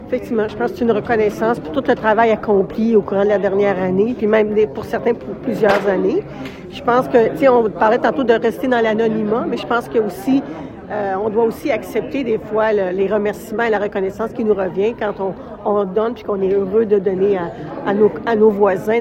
Les festivités de la 52e édition de la Semaine de l’action bénévole ont été lancées plus tôt mardi, lors d’une conférence de presse tenue à l’hôtel de ville.